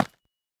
Minecraft Version Minecraft Version 1.21.5 Latest Release | Latest Snapshot 1.21.5 / assets / minecraft / sounds / block / calcite / place1.ogg Compare With Compare With Latest Release | Latest Snapshot